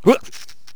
stickfighter_attack5.wav